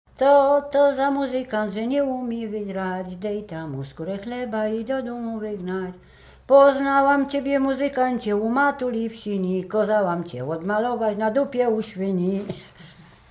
Łęczyckie
Przyśpiewki
weselne przyśpiewki do muzykantów